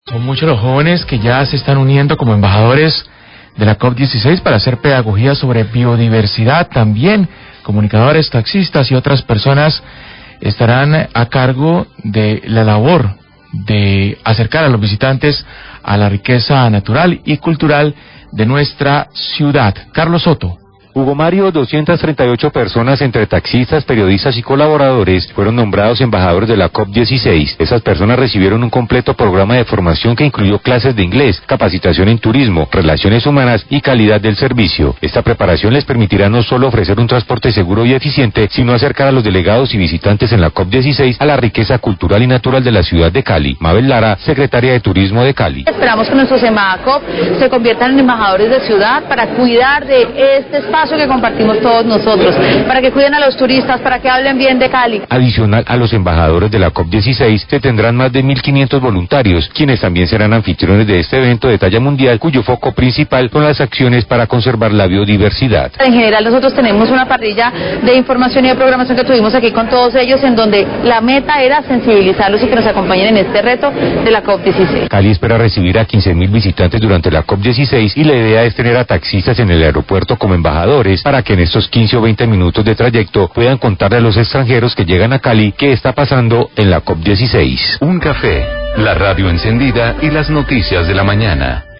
Radio
Mabel Lara, Secretaria de Turismo de Cali, habla del programa "Embajadores de la COP16" con el cual se busca acercar a los visitantes a la riqueza cultural y ambiental de la ciudad. Taxista, periodistas y colaboradores, recibieron capacitación al respecto.